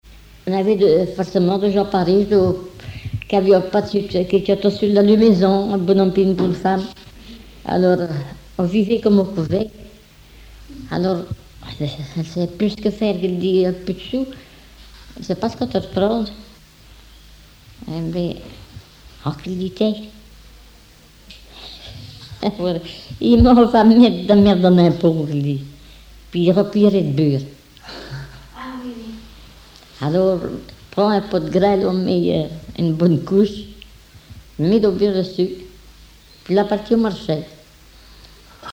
Genre conte
Enquête La Soulère, La Roche-sur-Yon
Catégorie Récit